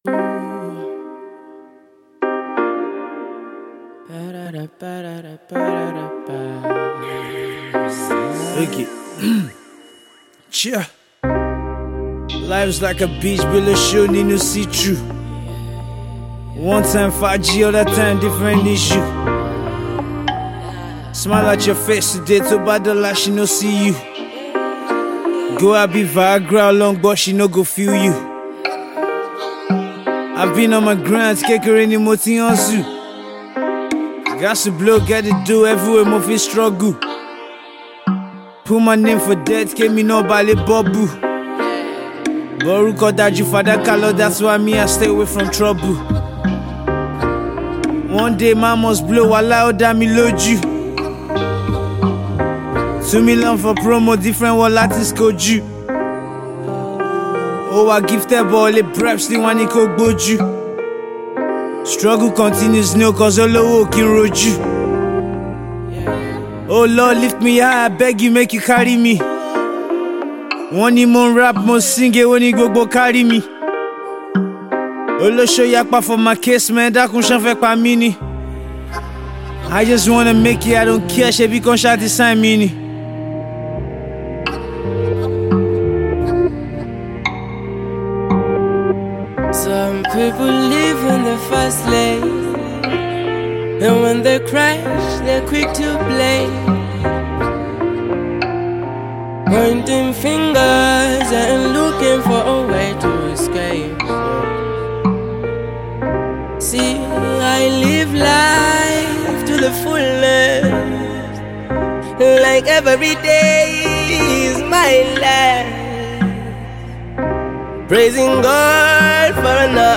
His latest freestyle visual